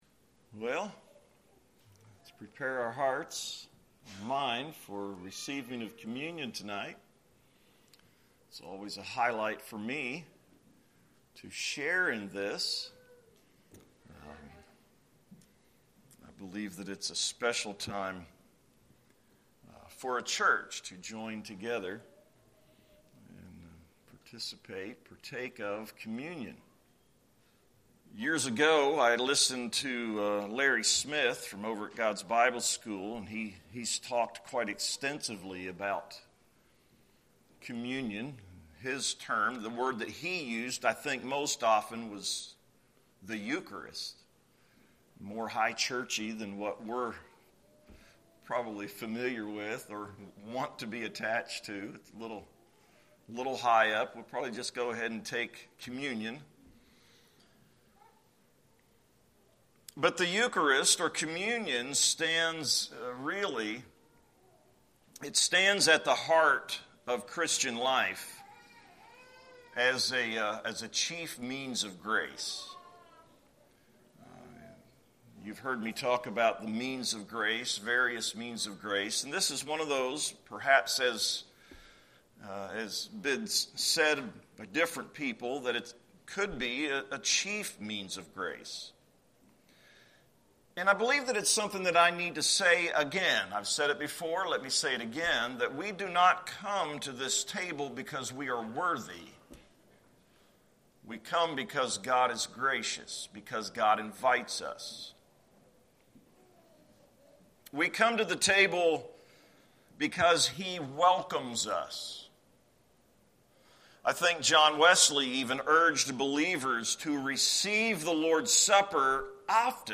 A communion meditation